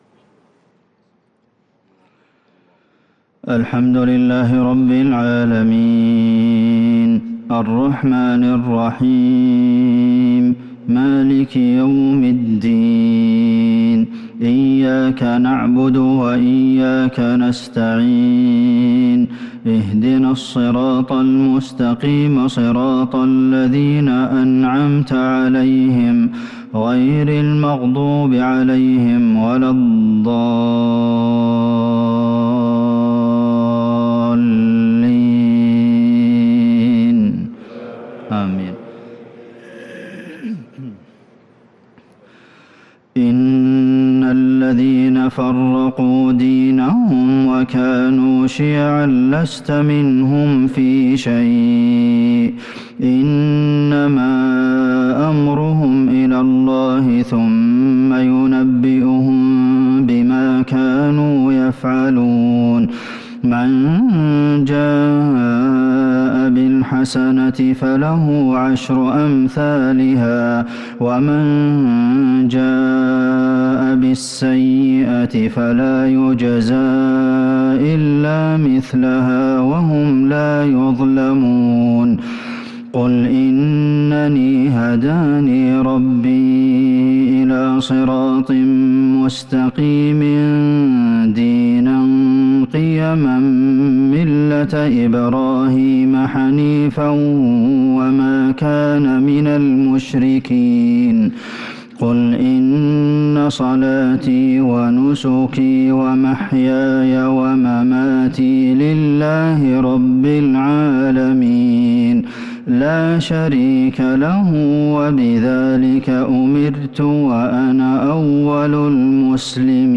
عشاء الخميس ٤ شوال١٤٤٣هـ | خواتيم سورة الأنعام | Isha prayer from Surah Al-An’aam 5-5-2022 > 1443 🕌 > الفروض - تلاوات الحرمين